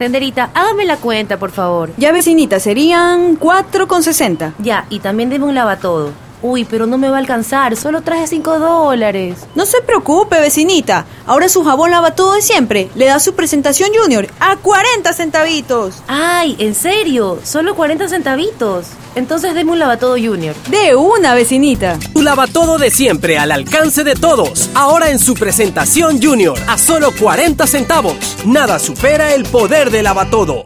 Locuiones de todo tipo: publicidad, doblaje, centrales telefĂłnicas (conmutador), documentales, etc. Acento neutro.
Sprechprobe: Sonstiges (Muttersprache):